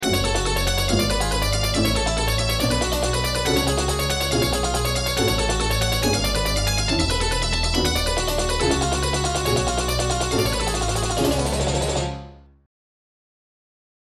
- > harmonic minor arpeggios
harmonic+minor+arpeggios.mp3